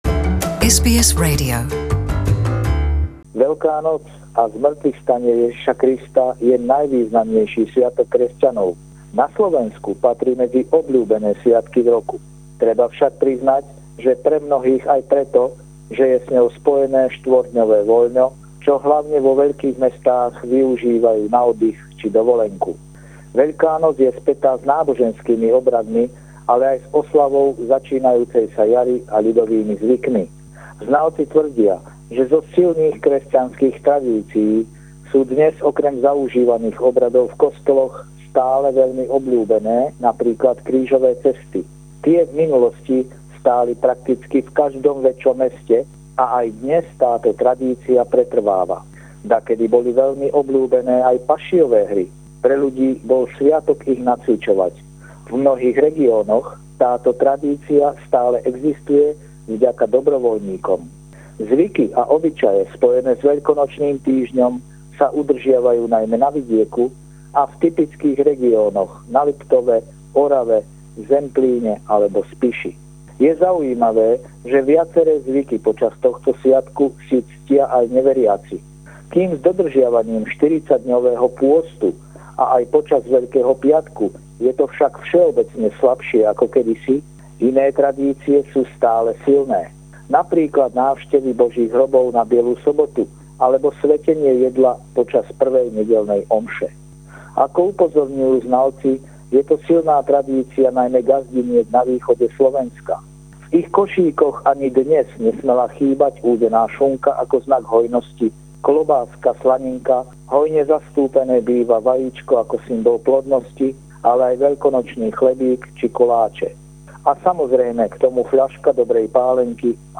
Tentoraz o Veľkej noci